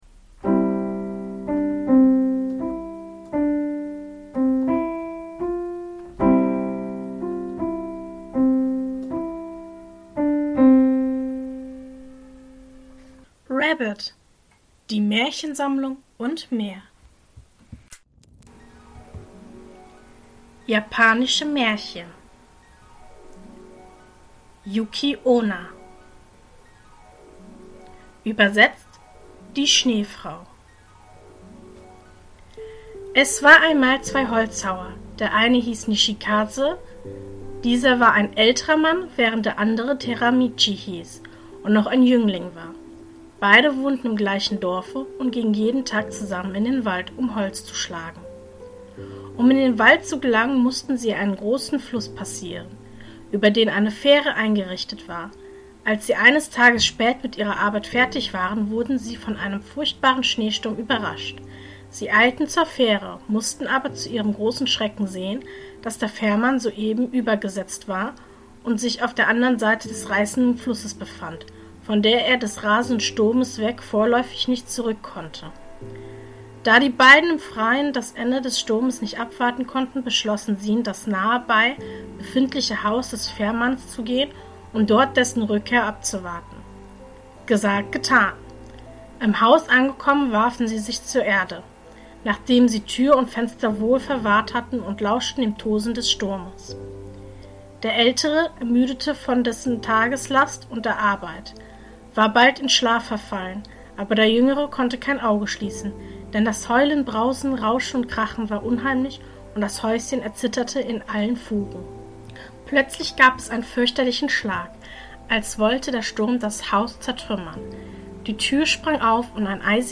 In der heutigen Folge lese ich Folgendes vor: 1. Juki -onna 2. Der weiße Fuchs Mehr